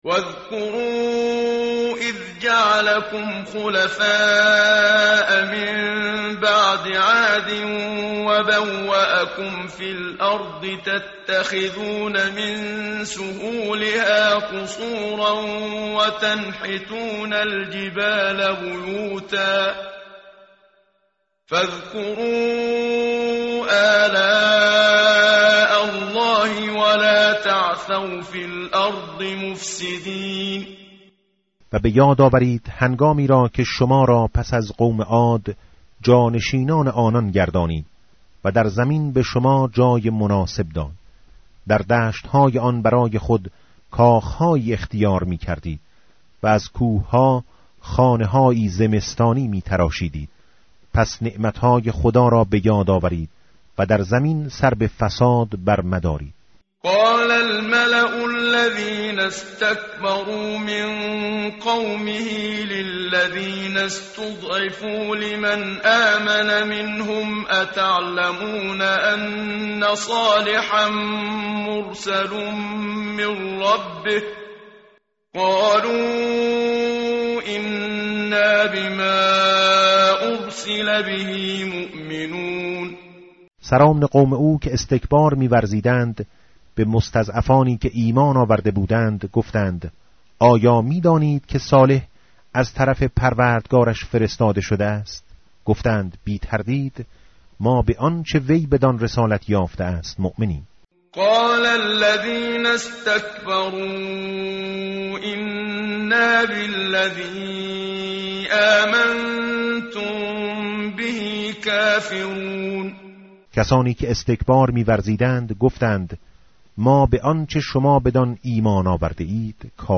tartil_menshavi va tarjome_Page_160.mp3